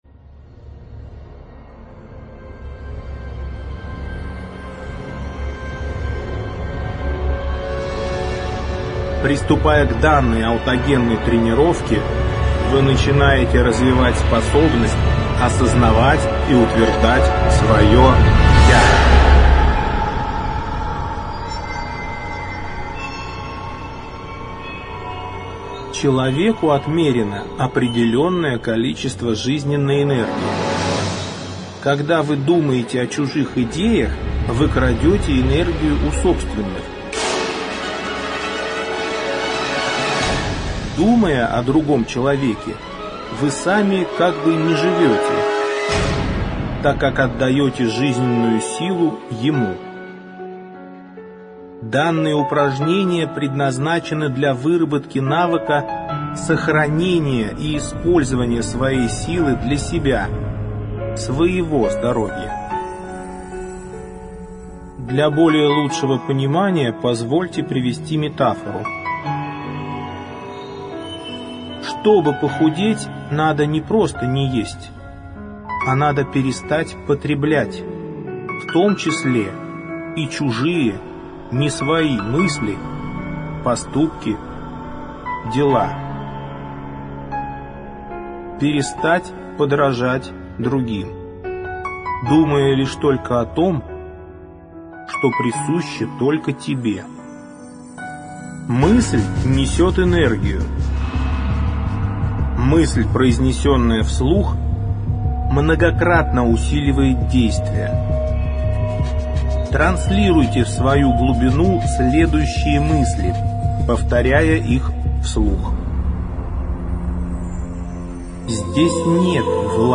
Аудиокнига Диск 4. Аутогенная тренировка «Я» | Библиотека аудиокниг